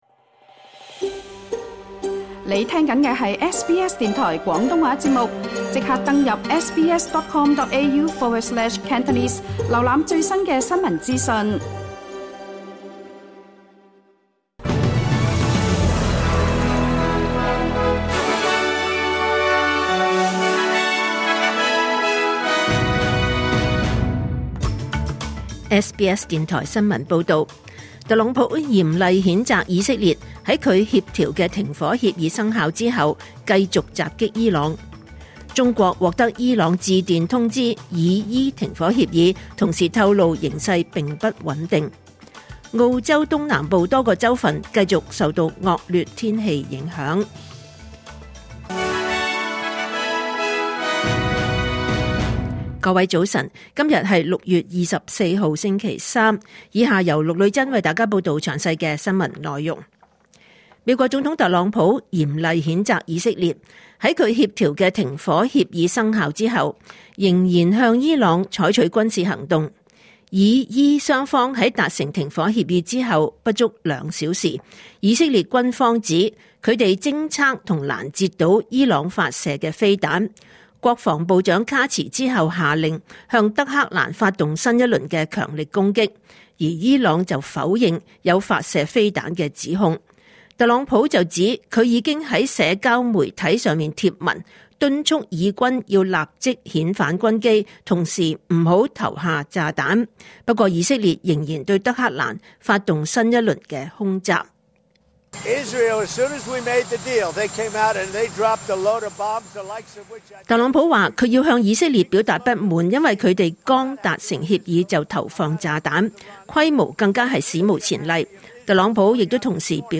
2025年6月25日SBS廣東話節目九點半新聞報道。